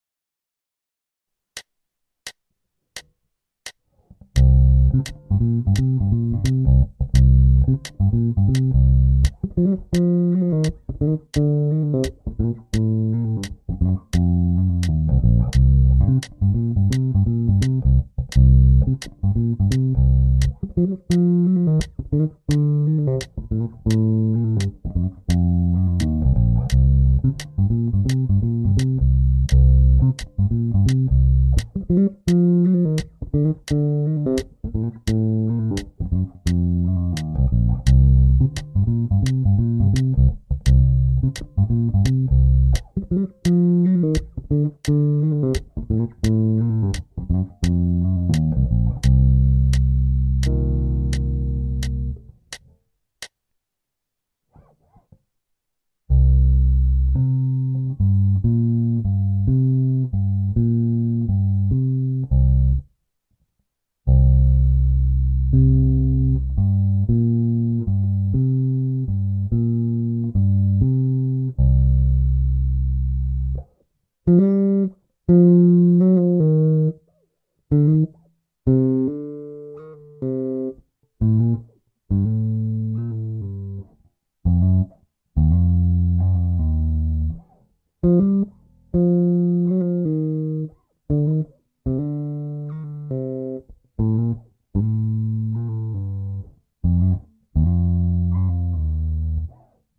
L37 Cm7 bas fill lick no3
L37-Cm7-fill-lick-no3.mp3